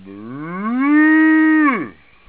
cow #1 (12k)
cow1.au